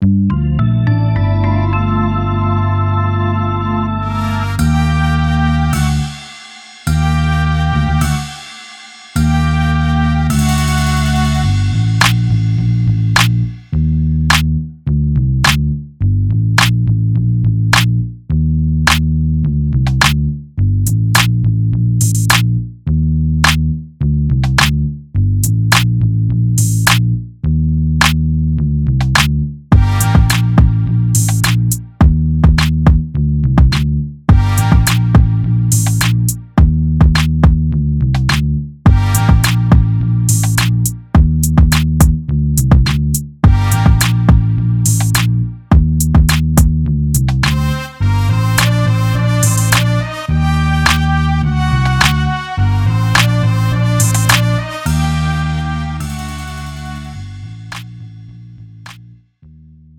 음정 남자키
장르 pop 구분 Pro MR